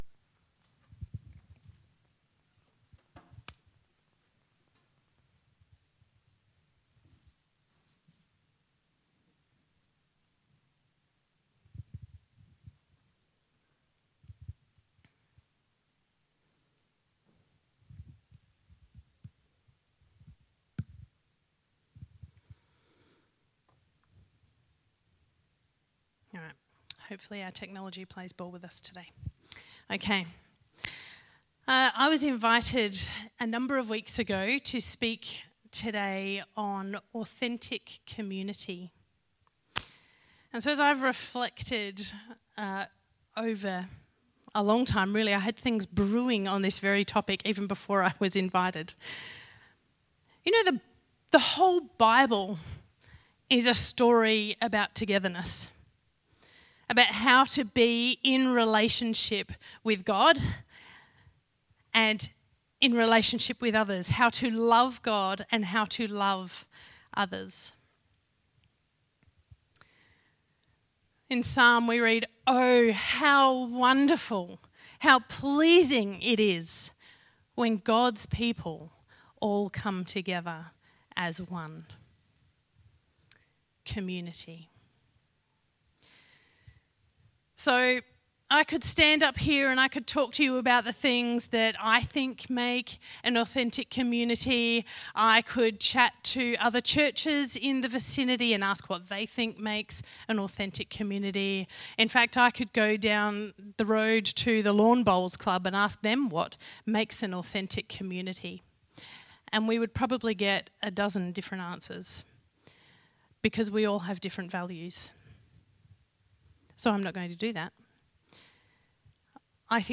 Sermons | Marion Vineyard Christian Fellowship